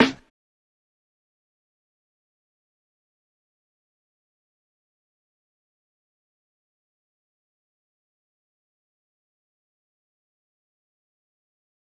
dilla snare.wav